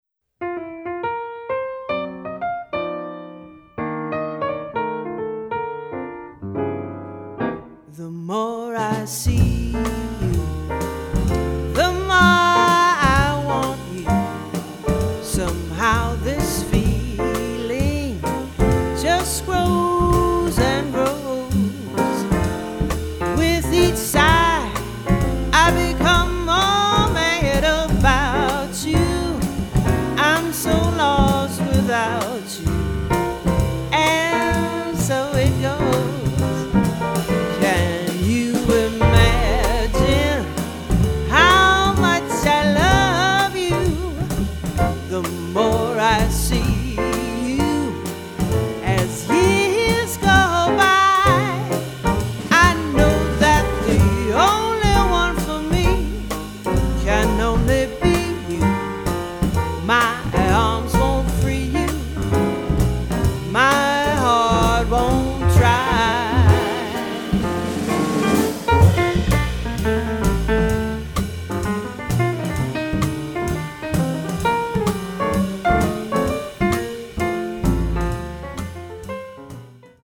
tenor sax and vocals
piano
bass
-drums